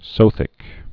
(sōthĭk, sŏthĭk)